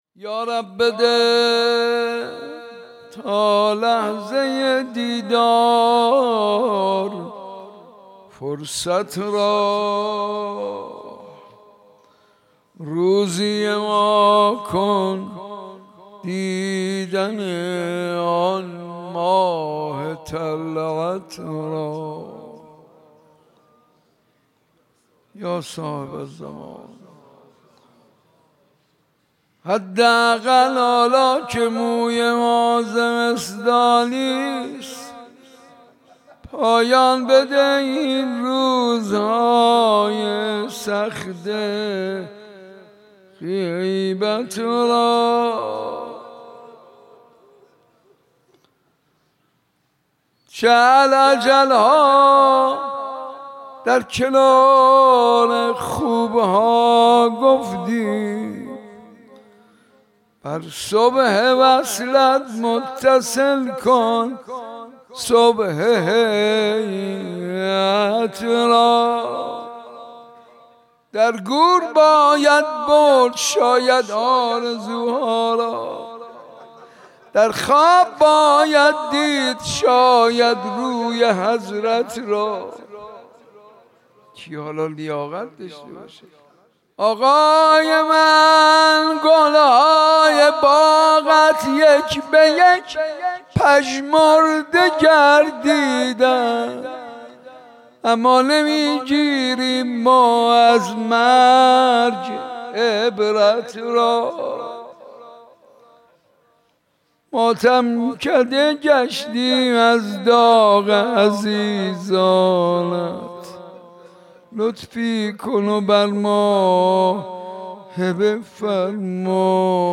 مداحی به سبک مناجات اجرا شده است.